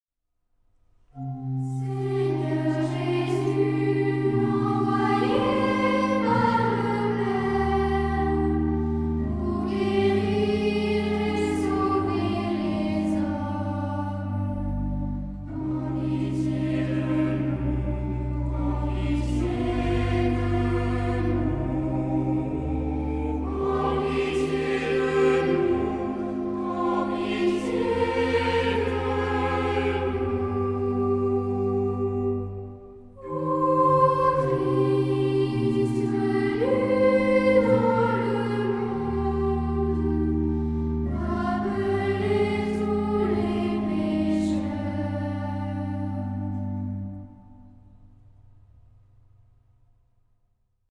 Tonality: C minor